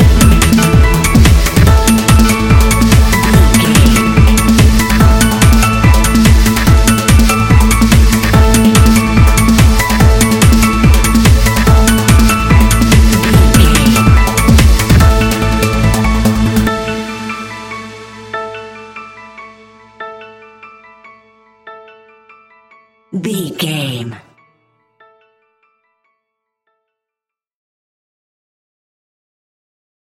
Ionian/Major
Fast
energetic
uplifting
hypnotic
drum machine
piano
synthesiser
acid trance
uptempo
synth leads
synth bass